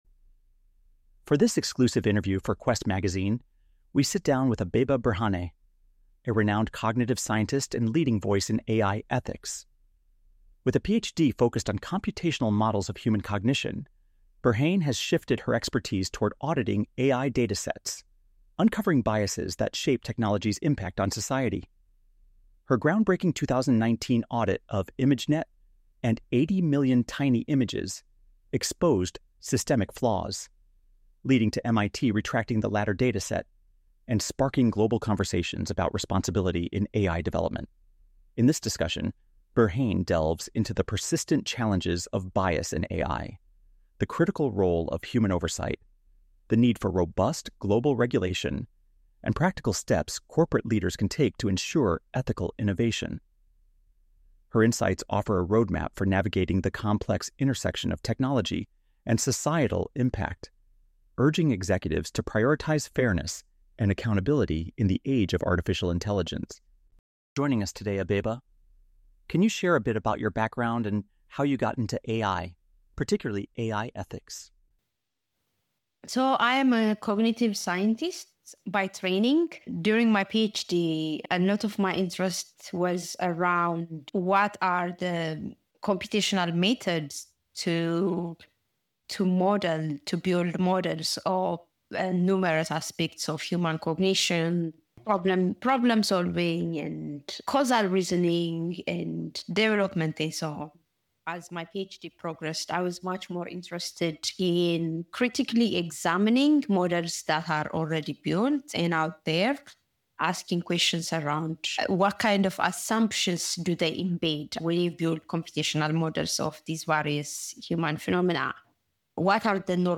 Download Audio version In this exclusive interview for QUEST Magazine, we sit down with Abeba Birhane, a renowned cognitive scientist and leading voice in AI ethics.